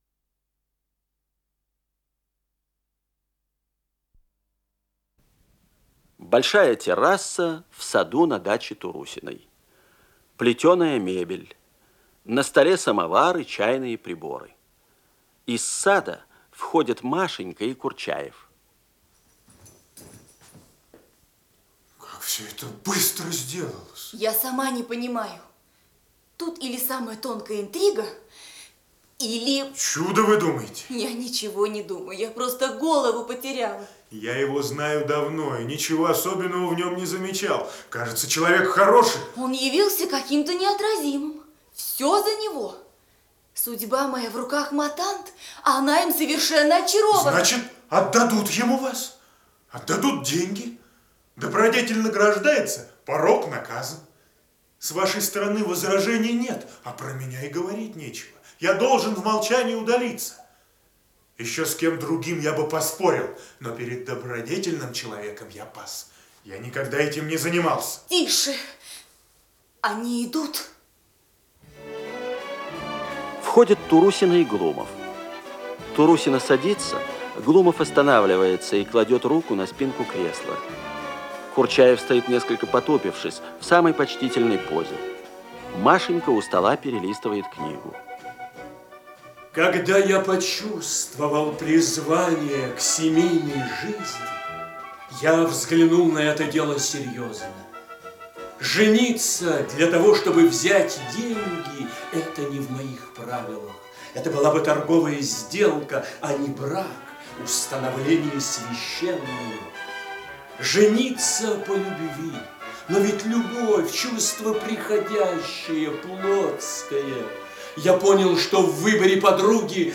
Исполнитель: Артисты Государственного академического Малого театра СССР
Спектакль Государственного академического Малого театра СССР